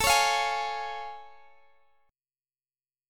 Listen to A+M7 strummed